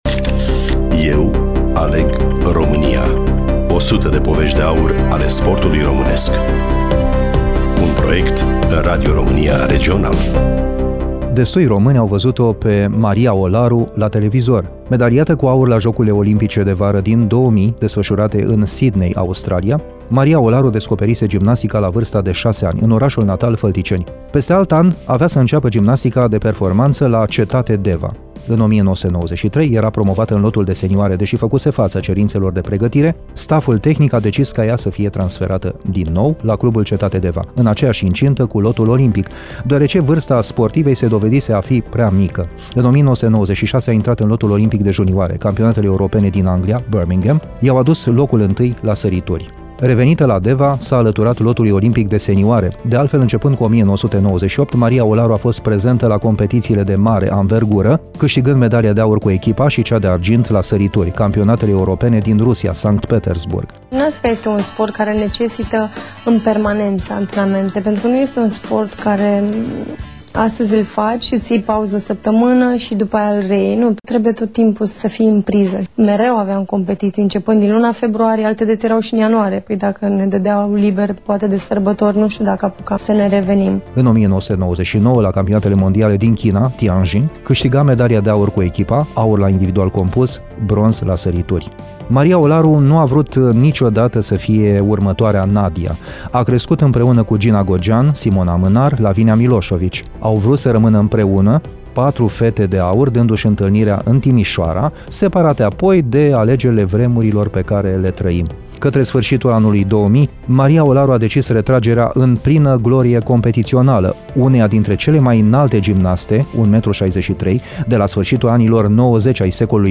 Studioul Radio Romania Iasi